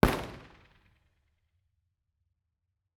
IR_EigenmikeHHR2_processed.wav